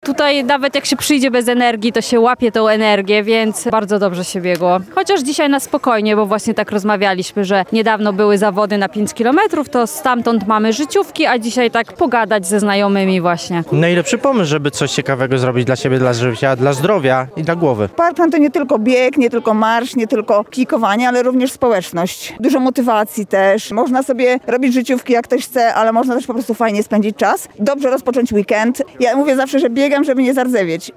Ja mówię, że biegam, aby nie zardzewieć – opowiadają uczestnicy.